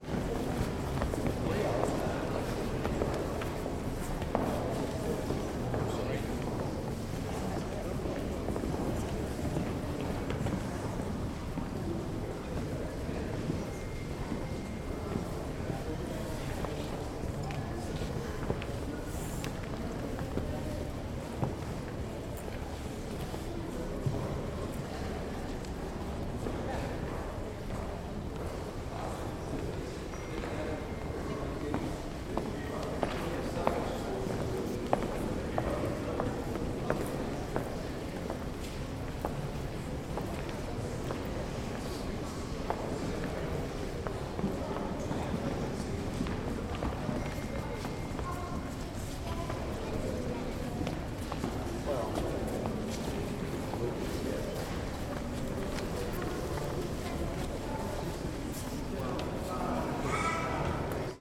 Здесь собраны атмосферные записи: от шороха шагов по паркету до приглушенных разговоров в выставочных залах.
Звуки музея, Лондонская Национальная галерея, атмосфера зала 1